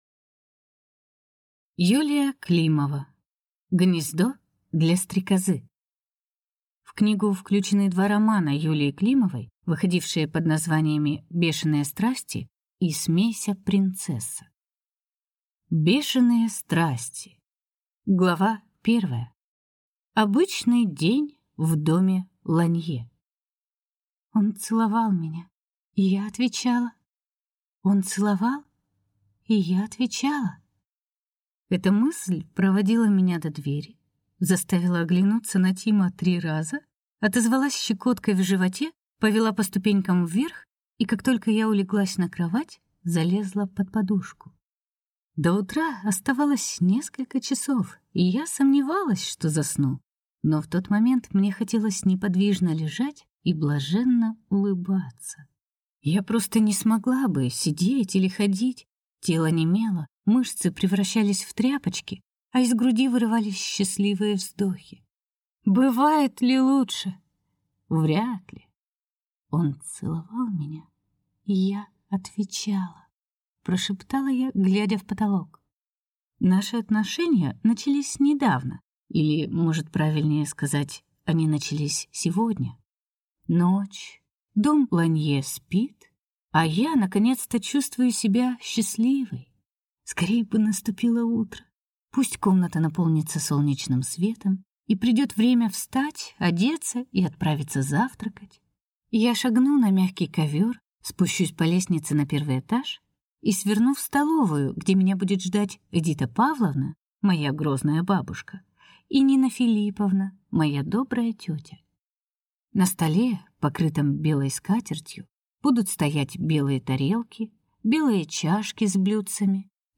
Аудиокнига Гнездо для стрекозы. Часть 2 | Библиотека аудиокниг